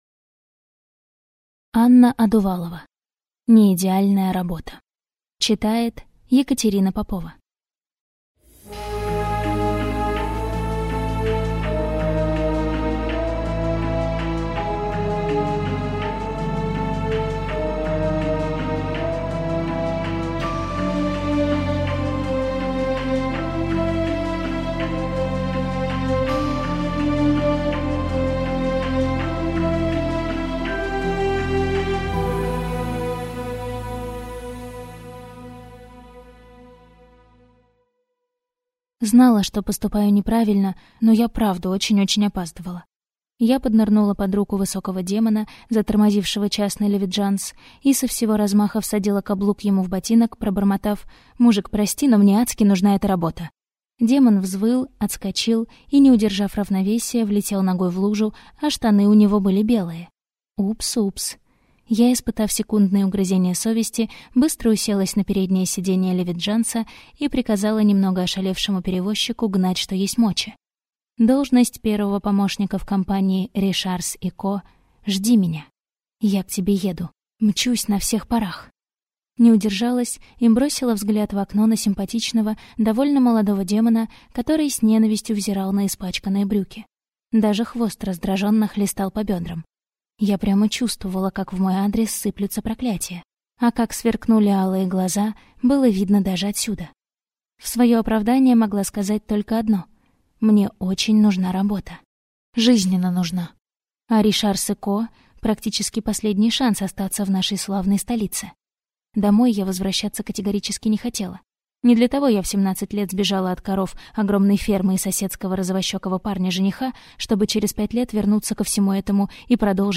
Аудиокнига (Не)идеальная работа | Библиотека аудиокниг